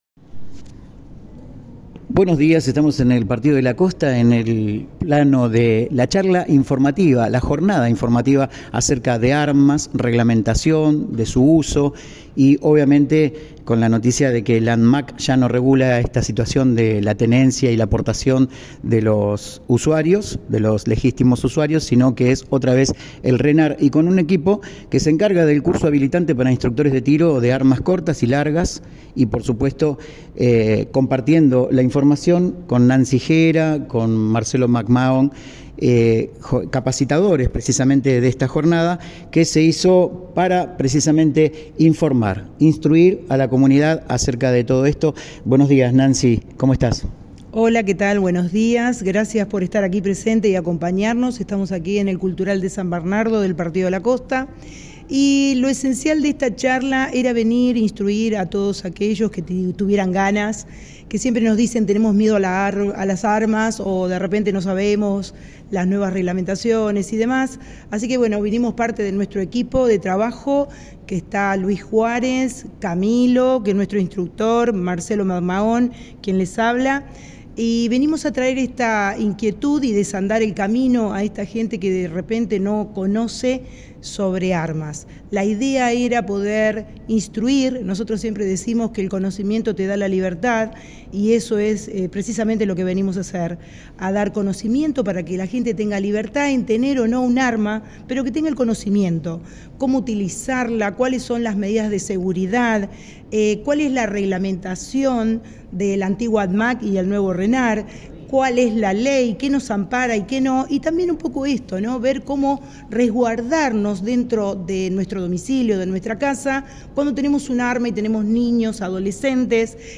Notas y Entrevistas realizadas en Om Radio
Este sábado 5 de julio, desde las 10 de la mañana, se llevó a cabo una jornada informativa en el Espacio Multicultural de San Bernardo, destinada a la comunidad de la Costa Atlántica.